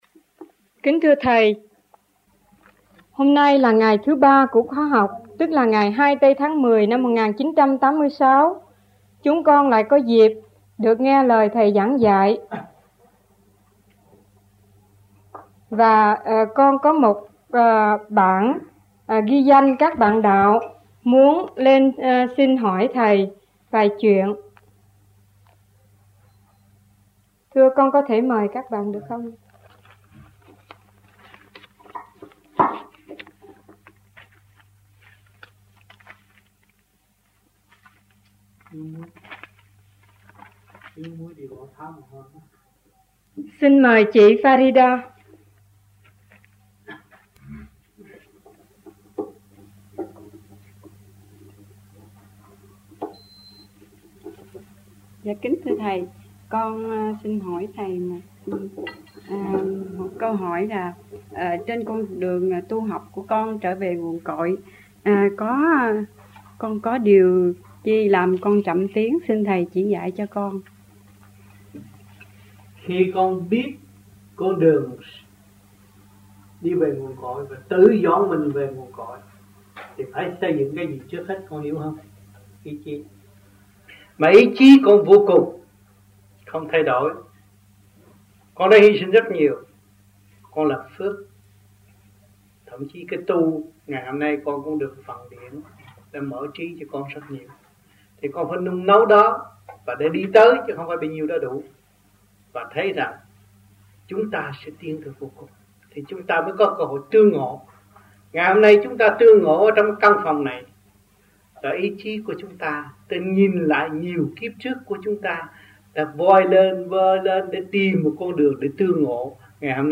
1986 Đàm Đạo
1986-10-02 - VẤN ĐẠO 03 - KHOÁ 3 - THIỀN VIỆN QUY THỨC